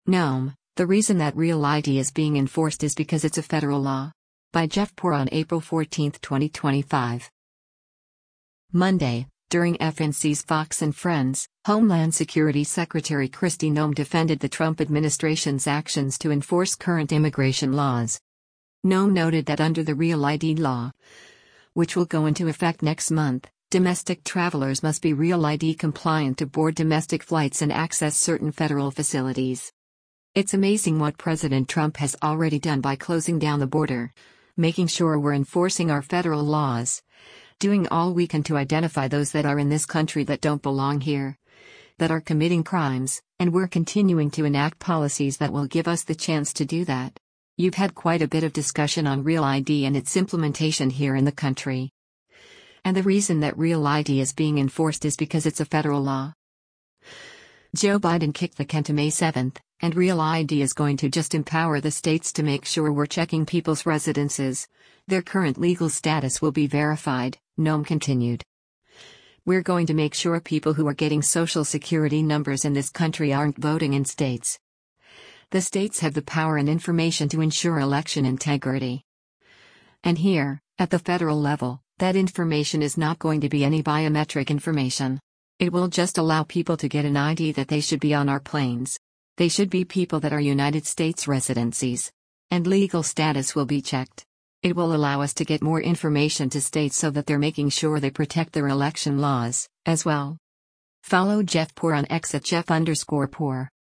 Monday, during FNC’s “Fox & Friends,” Homeland Security Secretary Kristi Noem defended the Trump administration’s actions to enforce current immigration laws.